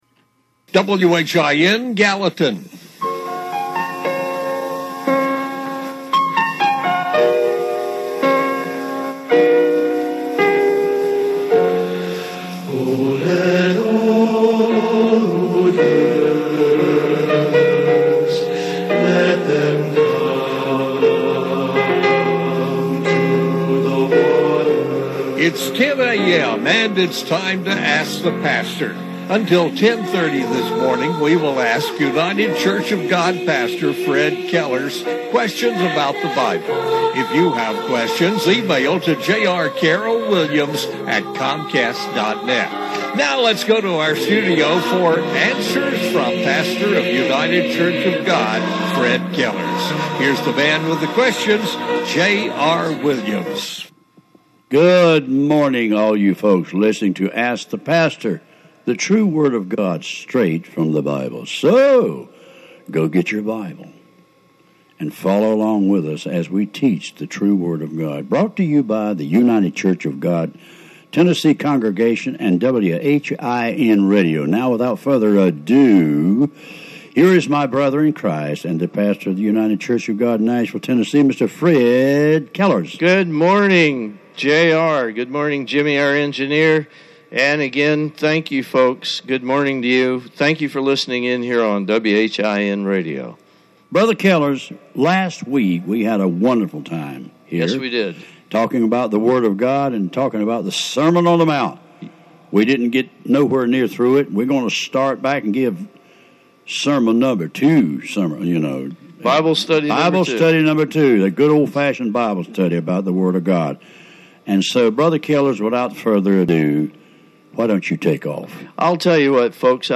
Radio broadcast from June 14, 2015 of "Ask the Pastor."